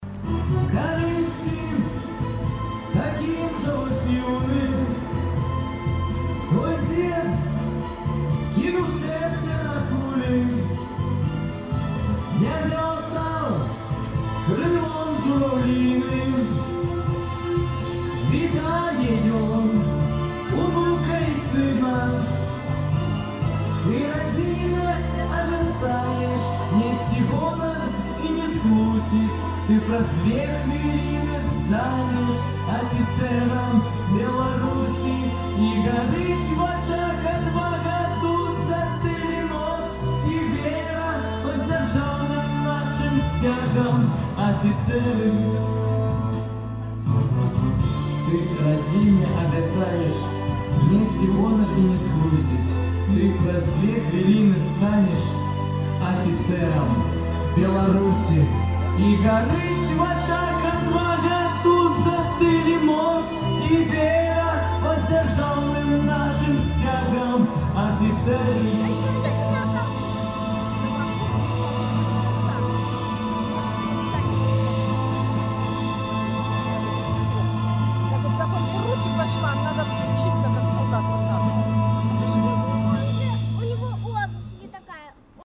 Маладое папаўненьне Магілёўскай кадэцкай вучэльні прымуць пад беларускамоўную песьню пра афіцэраў. Яе прэмʼера адбылася на сёньняшняй рэпэтыцыі ўрачыстасьцяў, прымеркаваных да новага навучальнага году.